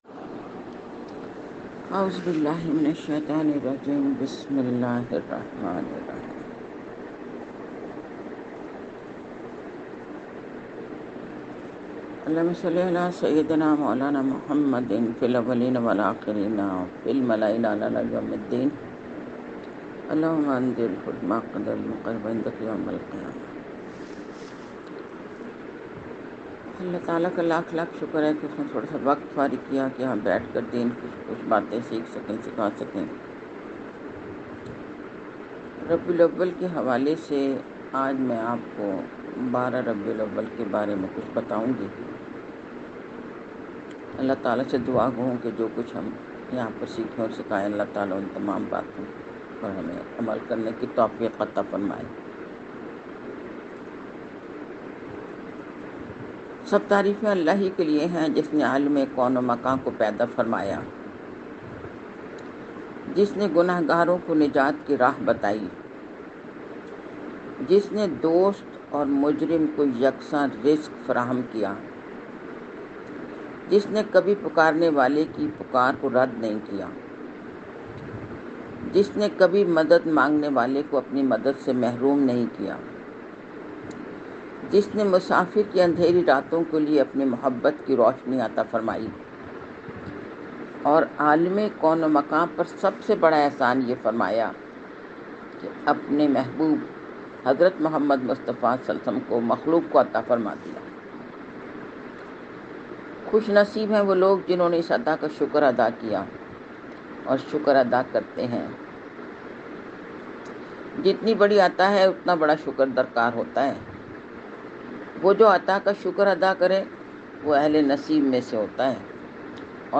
aac_merge-lecture.mp3